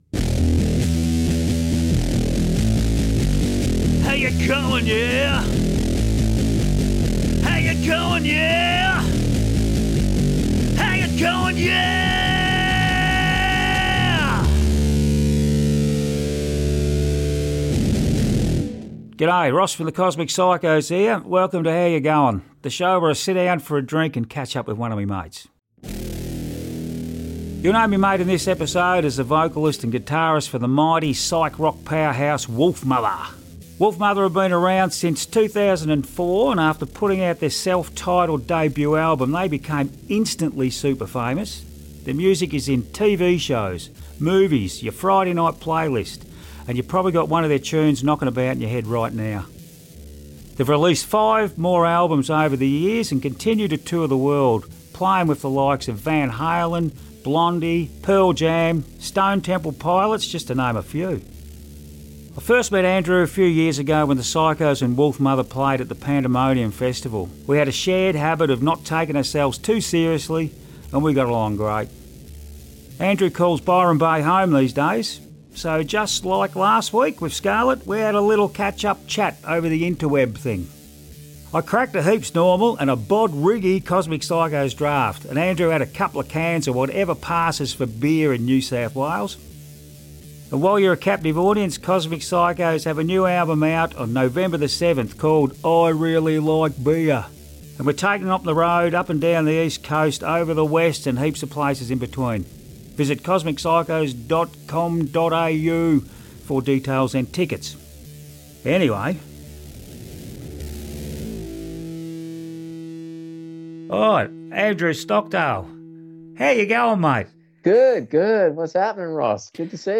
You’ll know my mate in this episode as the vocalist and guitarist for the mighty, psyc-rock powerhouse, Wolfmother.